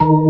FST HMND D#3.wav